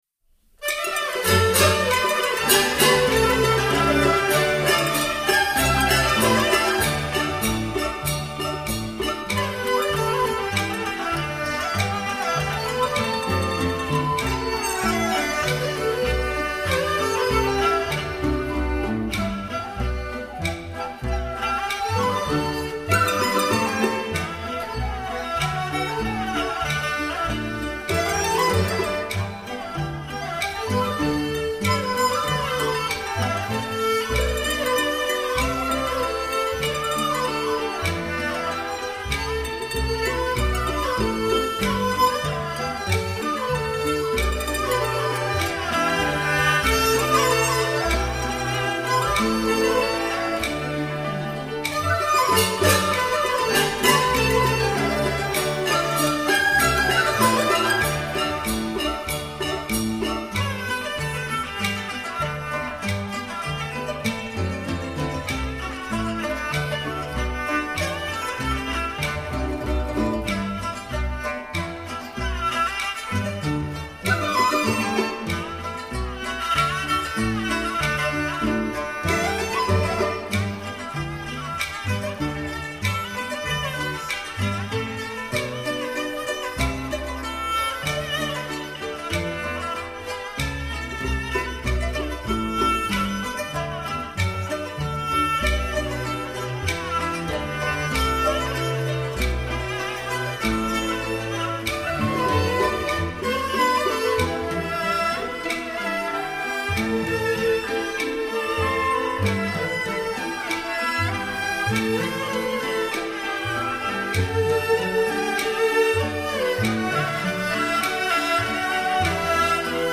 专辑类型：民乐